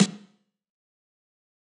Tell Your Friends Snare.wav